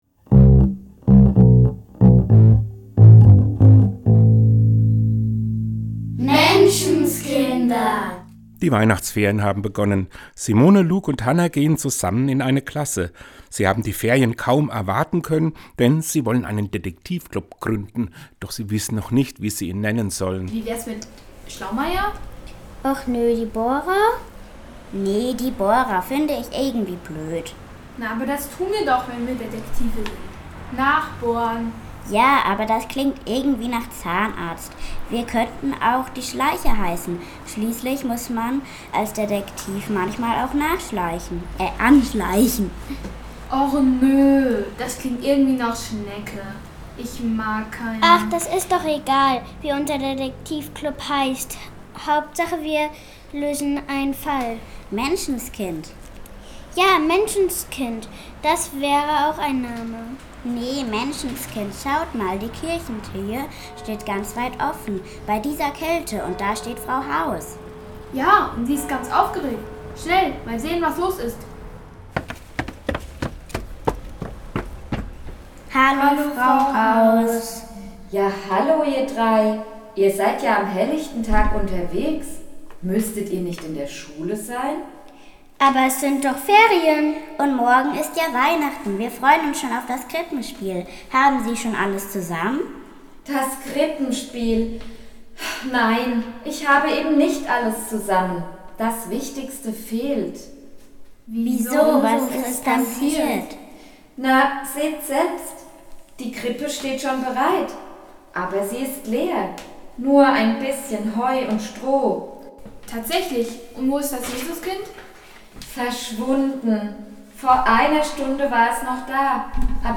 Teil 1 Hörspiel im Advent Vier Sonntage sind es bis zum Weihnachtsfest.